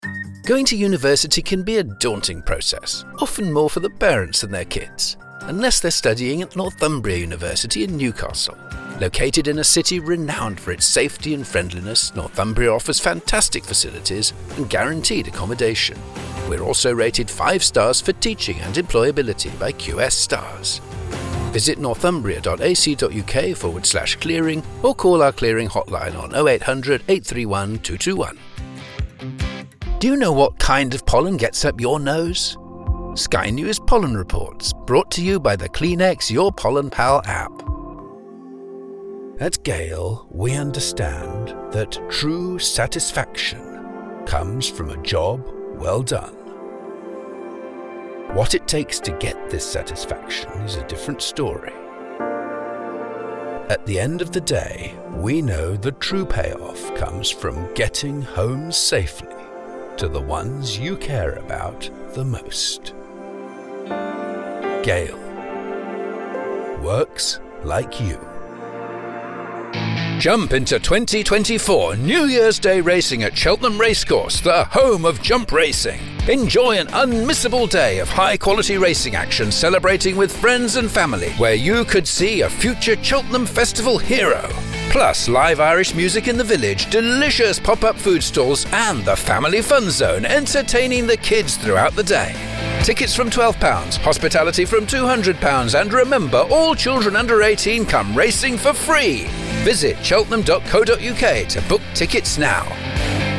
British Voiceover for Commercials:
Commercial Reel 2
I am able to interpret a script, and crucially to speak in a way which engages your audience, in a natural, engaging, conversational style rather than addressing the audience as though giving a speech.